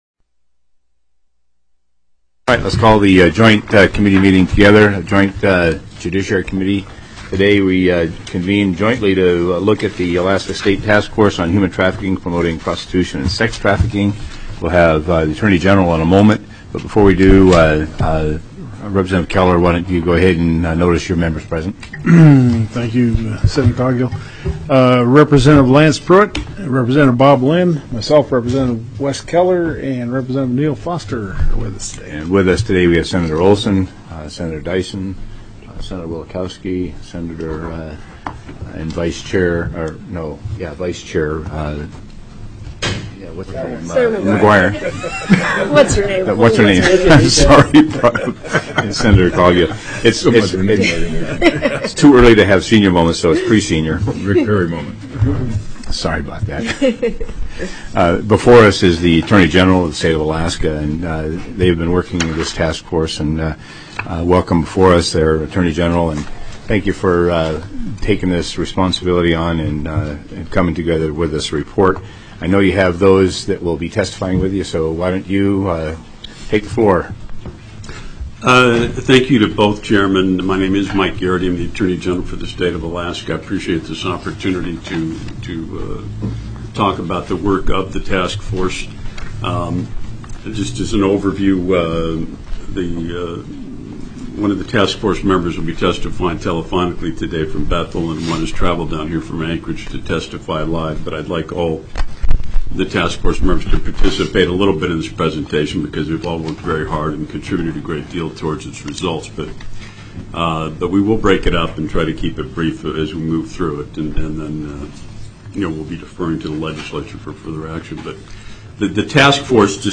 02/20/2013 01:30 PM House JUDICIARY
JOINT MEETING HOUSE JUDICIARY STANDING COMMITTEE SENATE JUDICIARY STANDING COMMITTEE